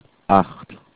Guttural ch
It's kinda like coughing up a loogie.
Gargling works as an example of the sounds on this page to some degree ...